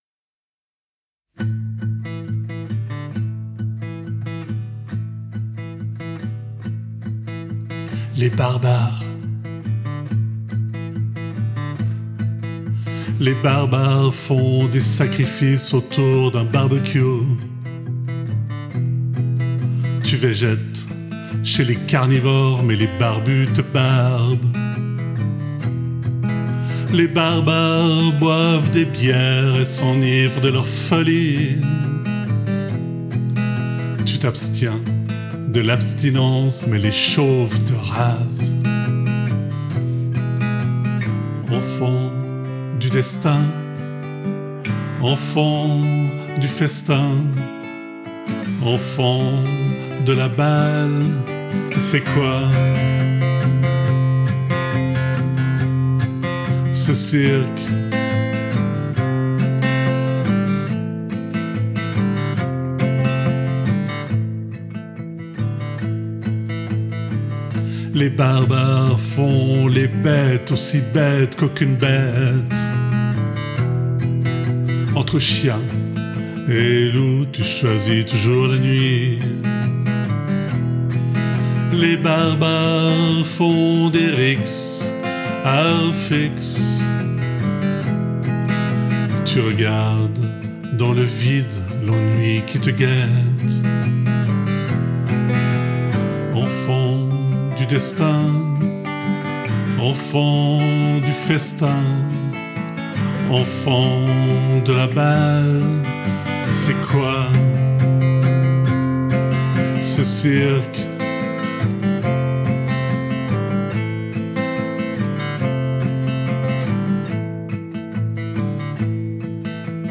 guitare, voix